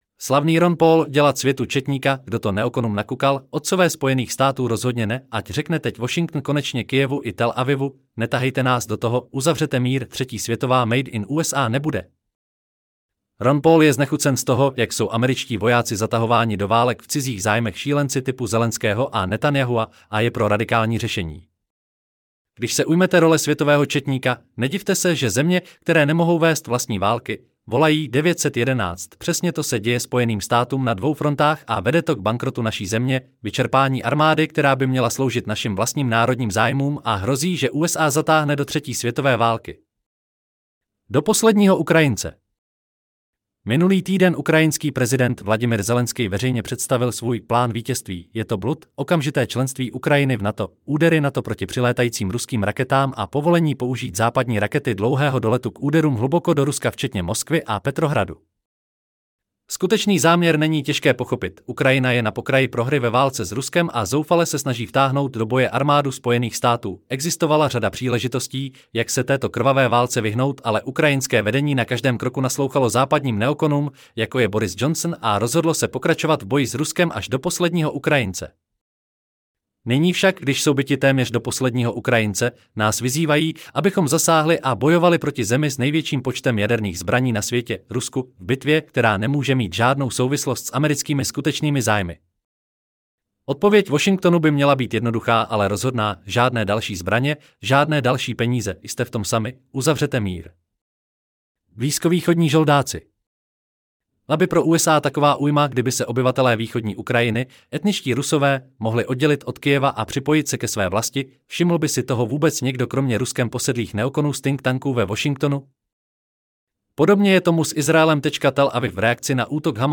Celý článek si můžete poslechnout v audioverzi zde: Slavny-Ron-Paul_-Delat-svetu-cetnika_Kdo-to-neoconum-nakukal_Otcove-Spojenych-statu-rozhodne-ne.At_ 7.11.2024 Slavný Ron Paul: Dělat světu četníka?